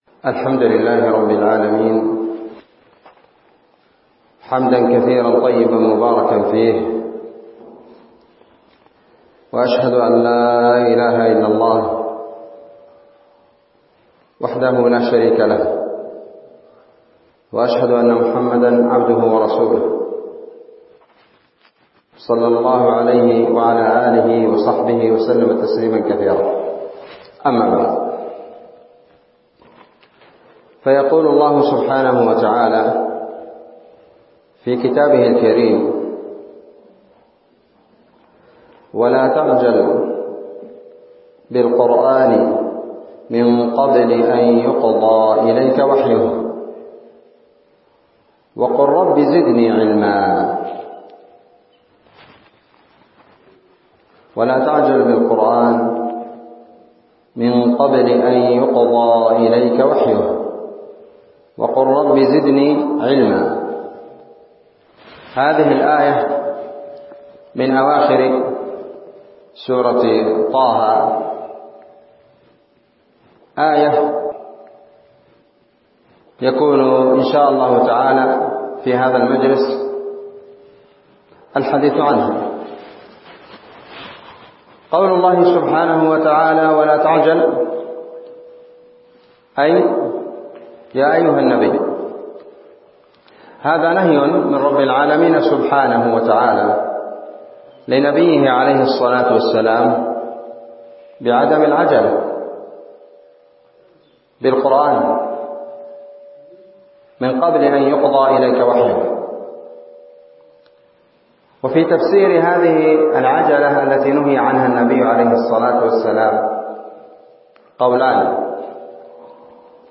كلمة بعنوان {وقل ربي زدني علما} 13 جمادى الآخرة 1444